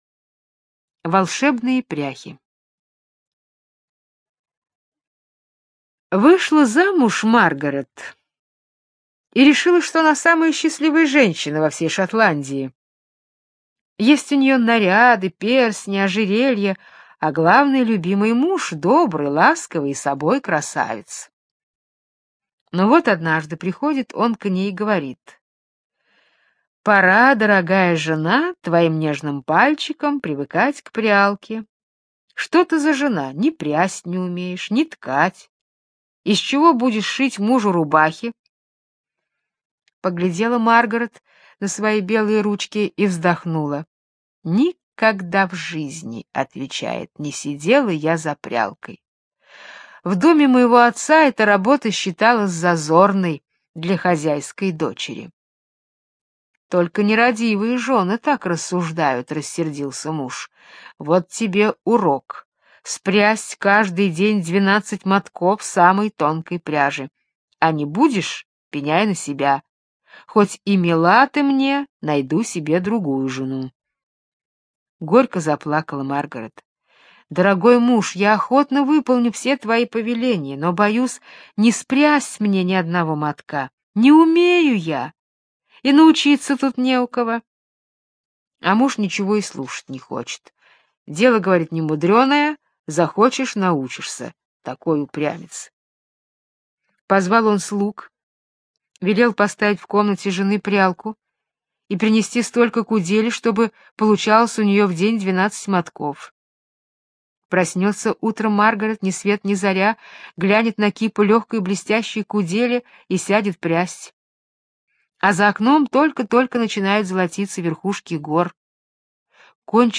Волшебные пряхи - британская аудиосказка - слушать онлайн